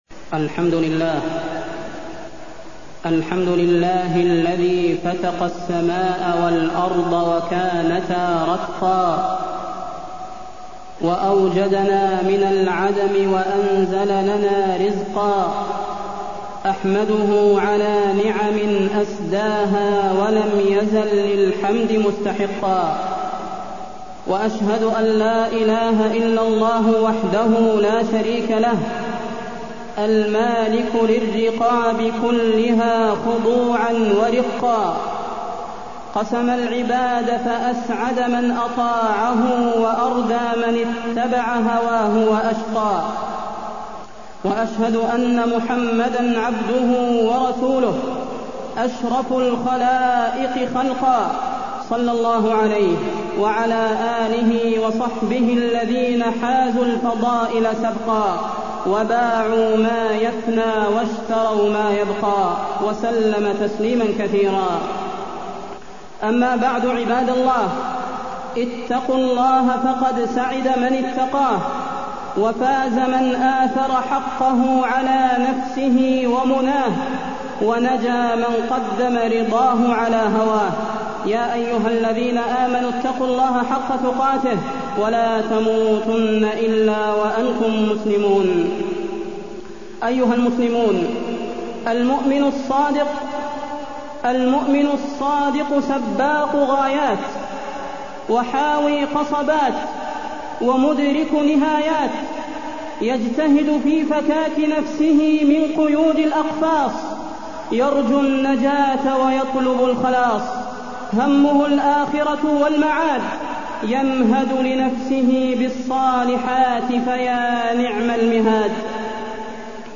تاريخ النشر ٢ ربيع الأول ١٤٢٢ هـ المكان: المسجد النبوي الشيخ: فضيلة الشيخ د. صلاح بن محمد البدير فضيلة الشيخ د. صلاح بن محمد البدير اتباع الهوى والشهوات